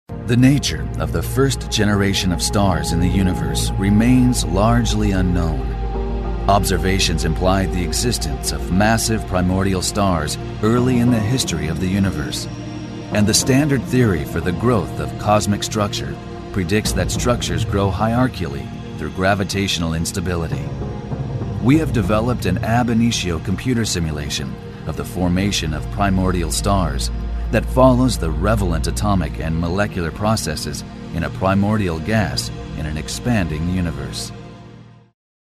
英語/北米・オセアニア地方 男性｜ナレーター紹介｜東京IMナレーター｜英語や韓国語をはじめ世界各国の言語でのナレーションをご提供